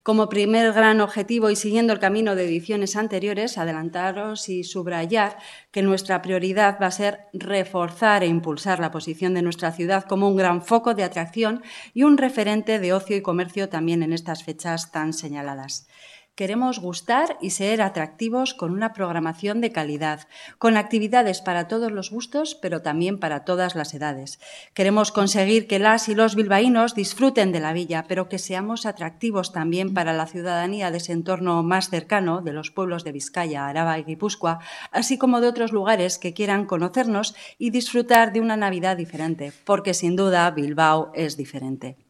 La Concejala Kontxi Claver destaca que el primer gran objetivo estas navidades es "reforzar" la posición de Bilbao